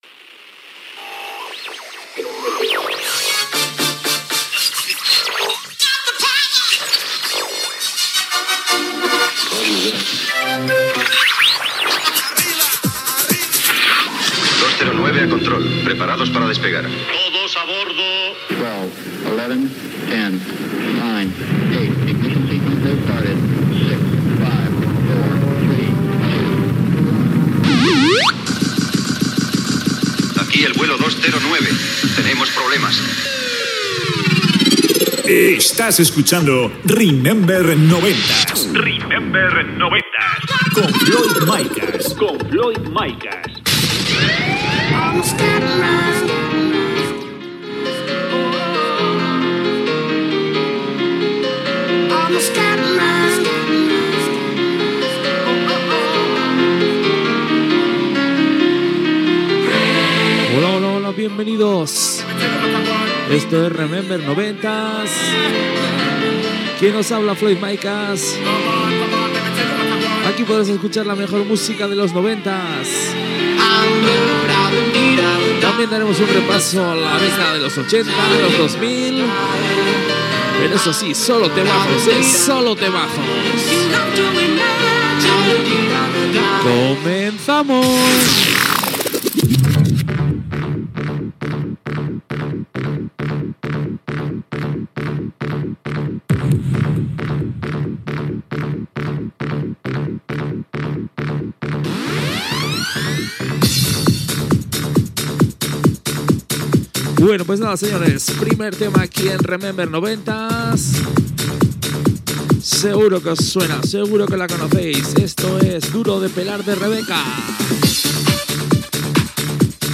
Careta del programa, presentació i tema musical
Musical